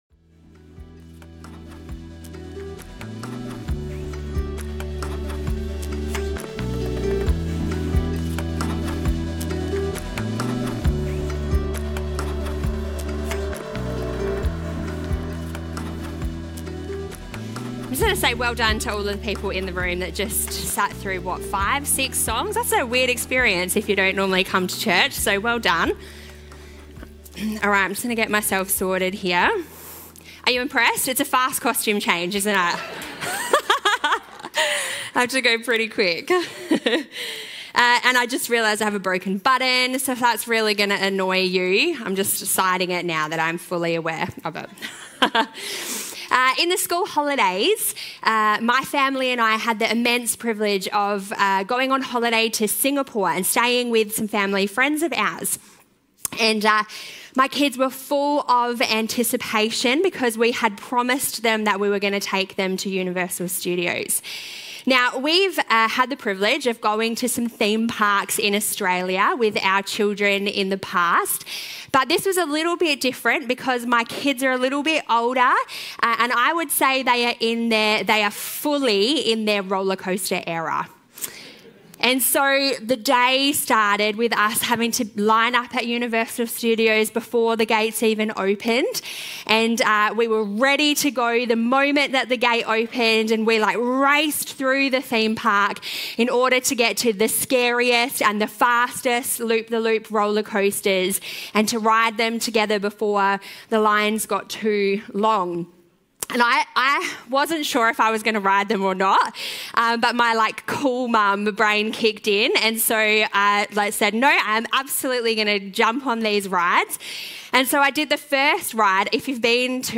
Baptism Sunday